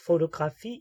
Ääntäminen
IPA : /ˈfəʊtəɡɹɑːf/